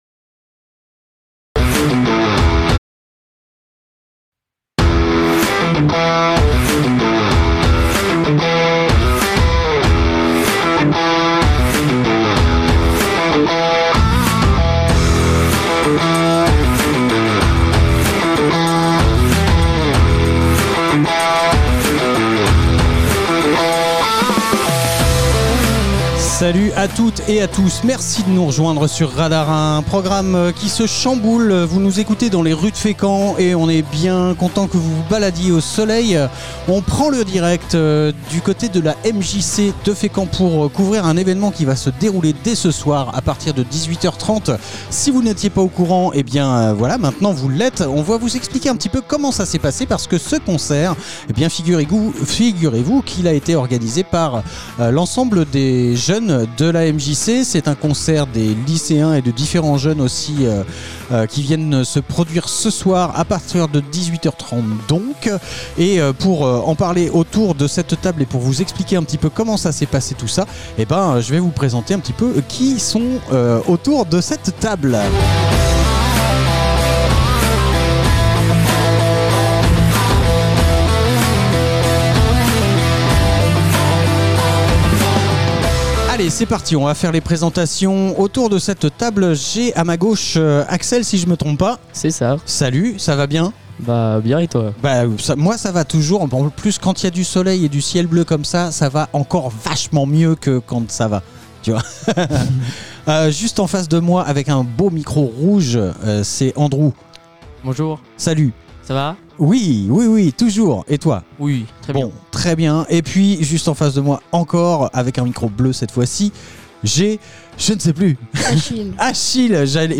Le studio mobil est une caravane entièrement équipée pour produire des émissions couvrant différents événements. On y reçoit les acteurs et participants de ces manifestations pour les interviewer en direct (quand les connexions sont possibles) ou en conditions de direct, ce qui donne de nombreux podcasts à retrouver ici.